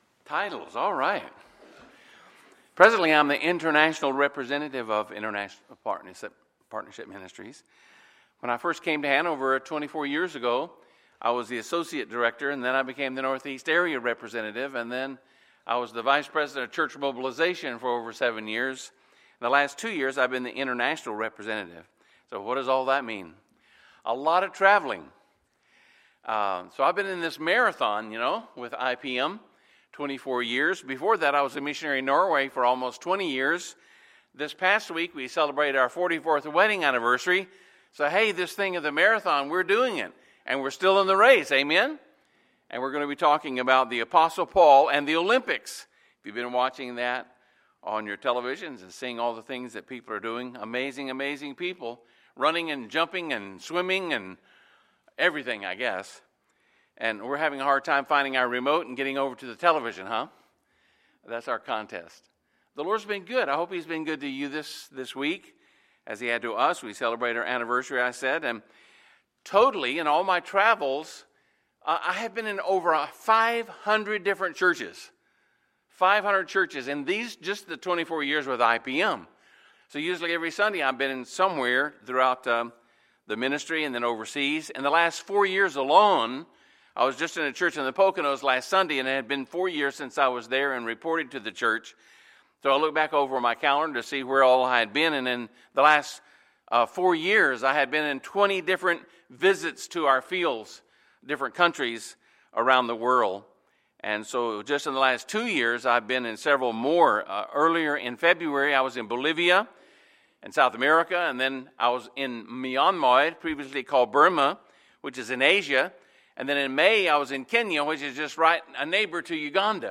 Sunday, August 14, 2016 – Sunday Morning Service